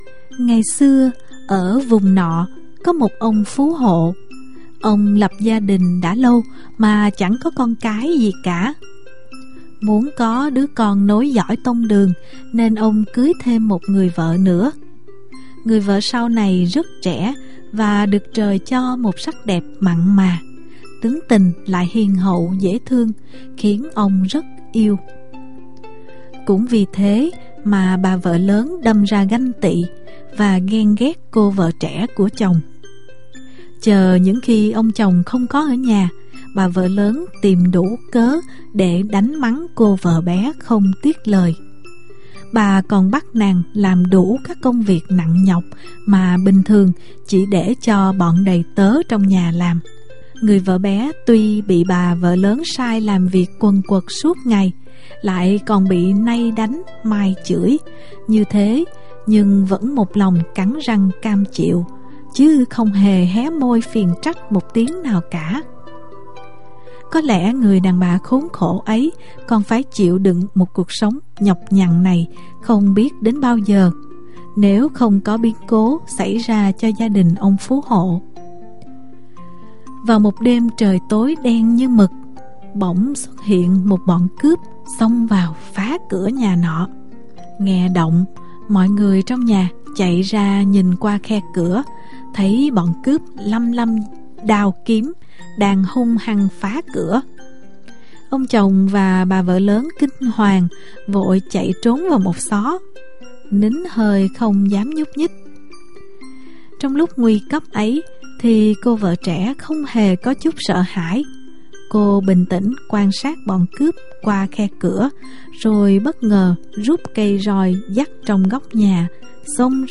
Sách nói | Người vợ tài đức